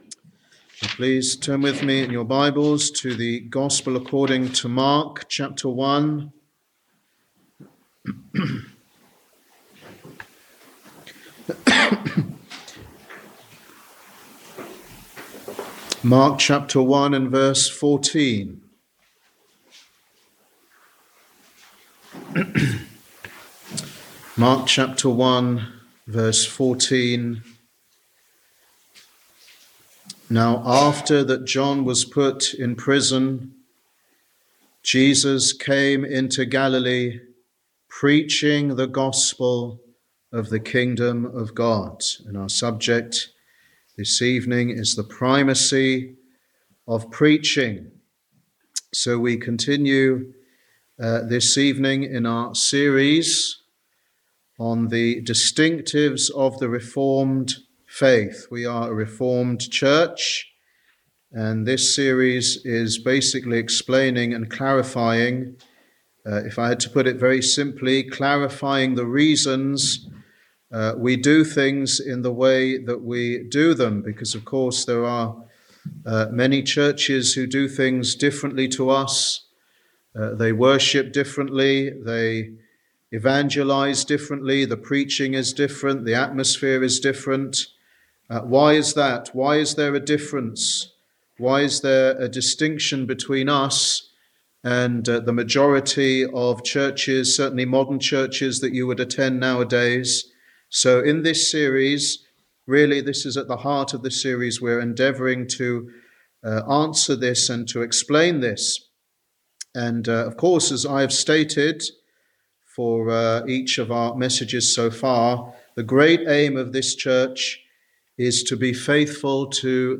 Wednesday Bible Study
Sermon